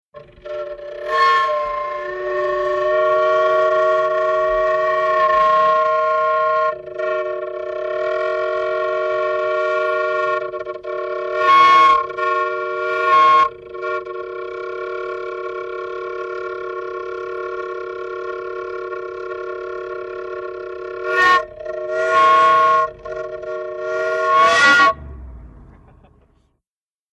1919 Packard|Horns | Sneak On The Lot
VEHICLES - HORNS 1919 PACKARD: Horn, exhaust whistle.